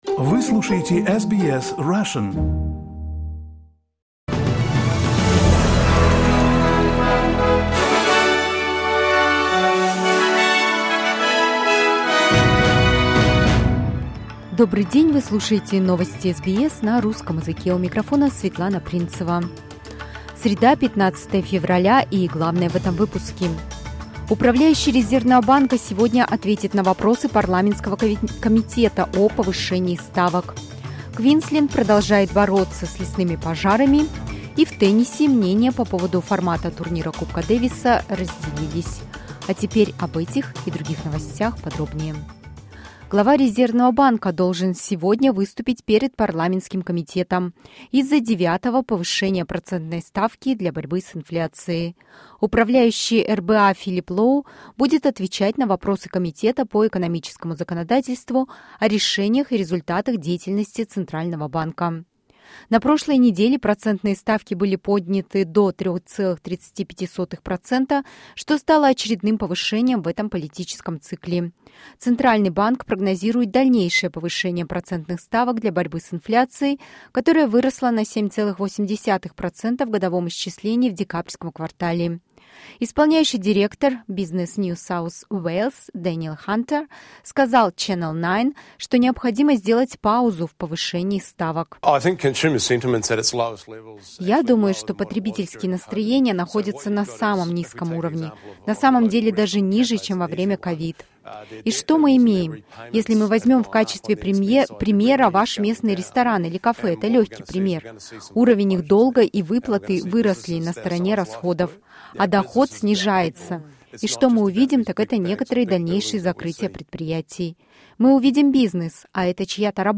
SBS news in Russian — 14.02.2023